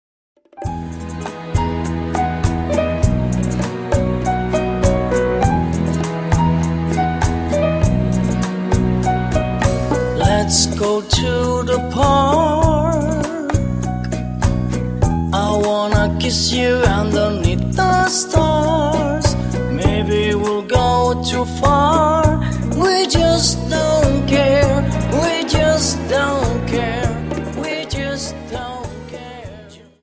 Dance: Rumba 25 Song